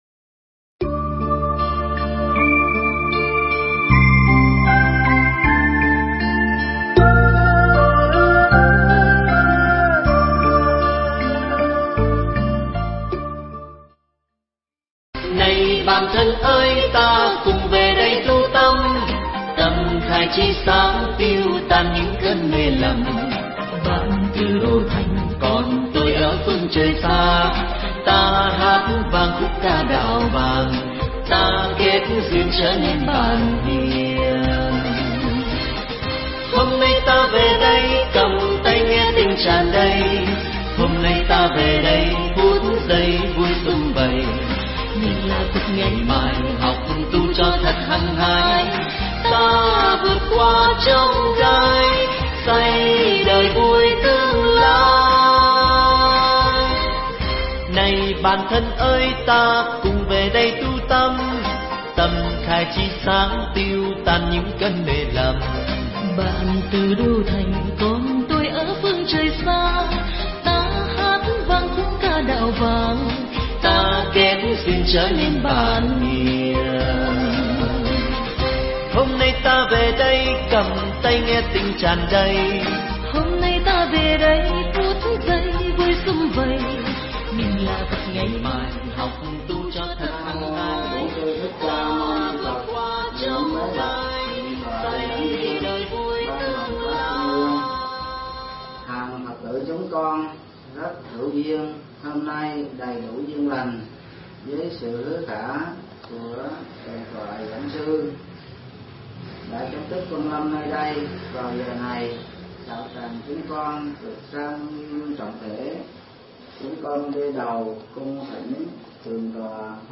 Mp3 thuyết pháp
giảng tại chùa Hoa Lâm (Bình Thuận) trong khoá tu Một Ngày An Lạc lần thứ 2 năm 2012